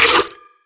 rifle_pickup1.wav